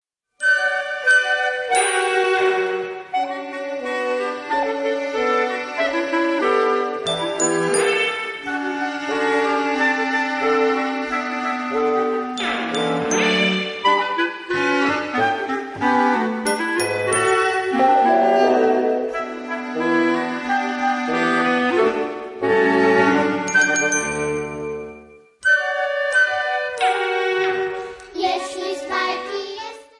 Children's Stories Read in Polish with Accompanying Music.